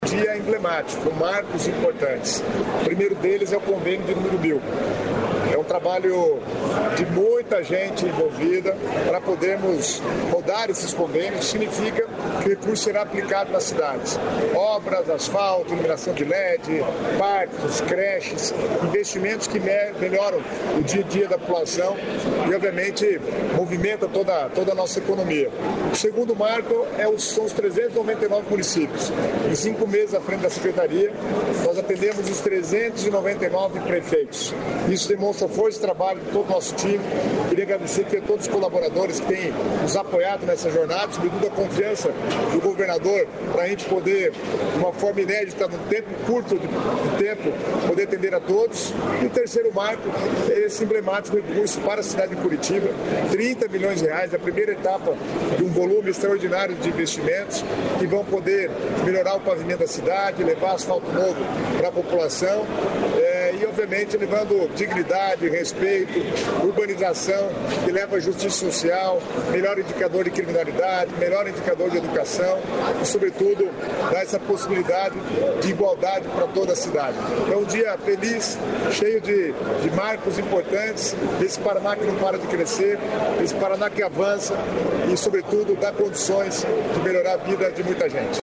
Sonora do secretário de Estado das Cidades, Guto Silva, sobre mil convênios firmados com municípios